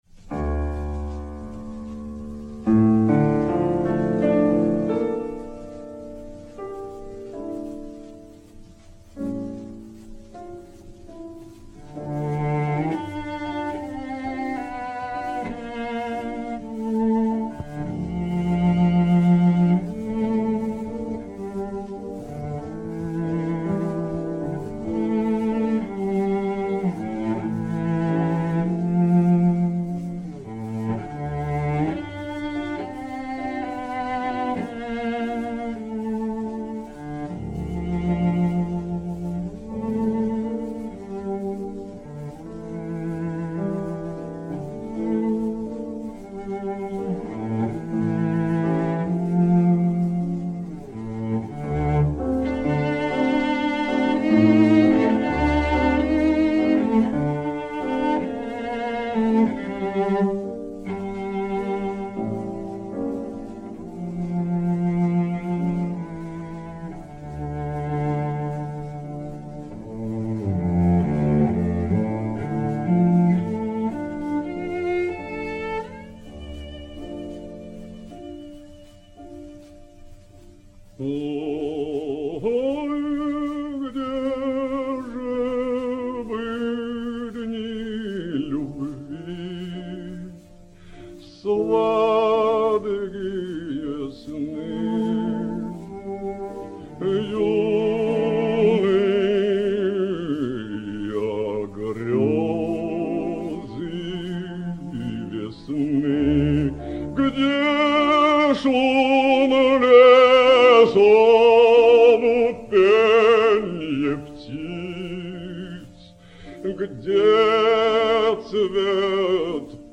basse
violoncelle
piano